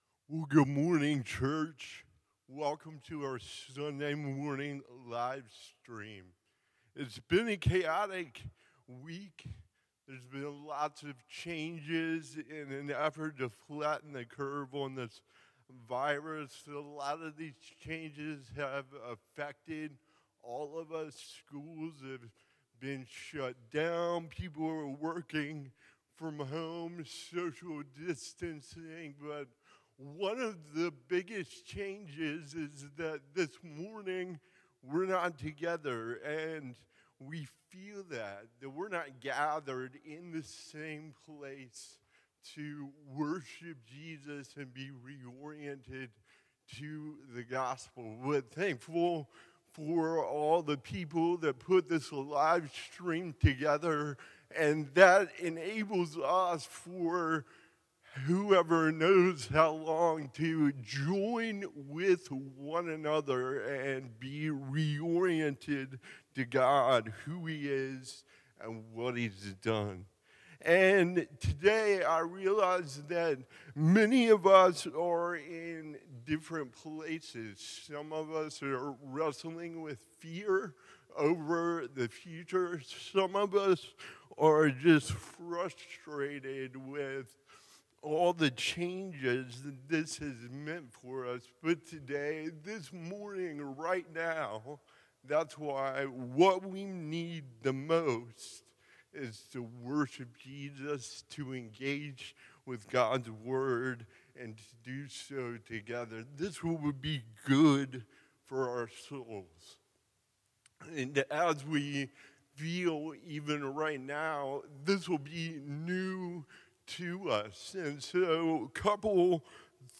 Crossway Community Church